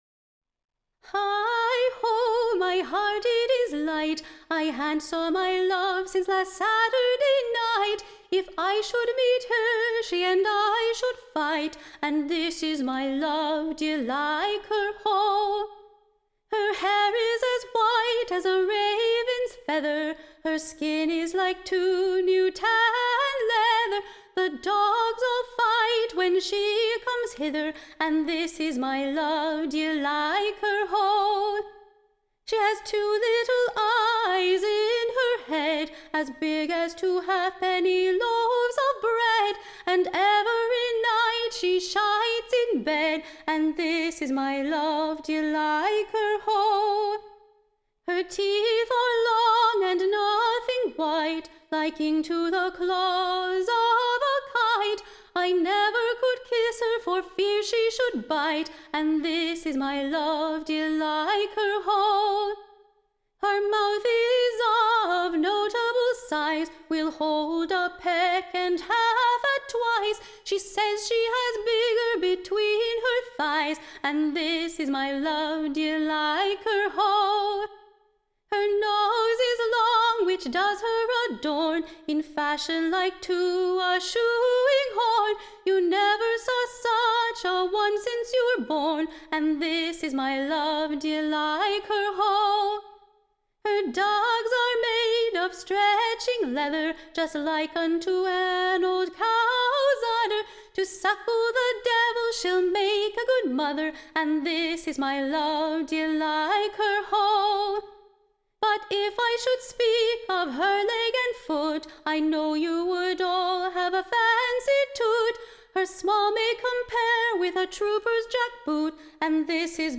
Recording Information Ballad Title THE / Well-shap'd West-Country Lass, / Set forth in her Proper SHAPES and QUALITIES. / She is so Fair in her Degree, / that most she does Surpass, / I fear that many now will be / in Love with this Fair Lass; Tune Imprint To the Tune of, Cavalilly Man.